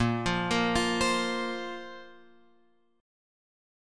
A#sus2 chord {6 3 3 3 6 6} chord